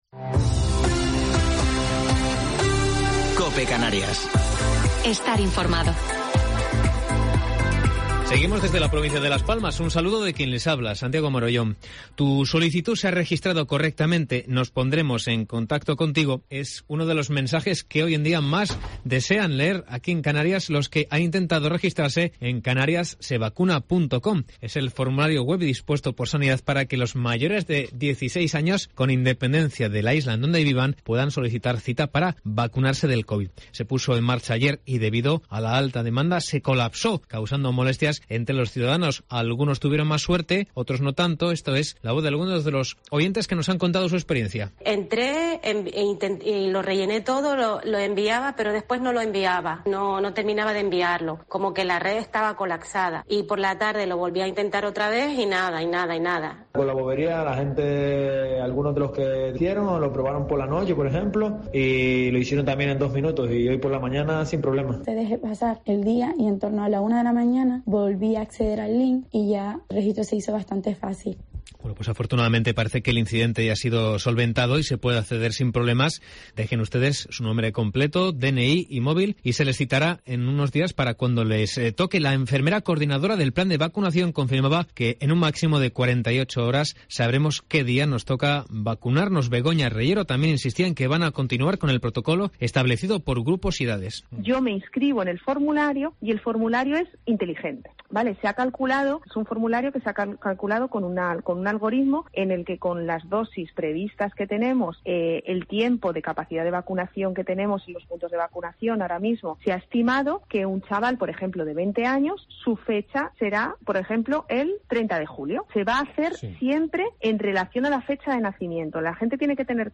Informativo local 25 de Mayo del 2021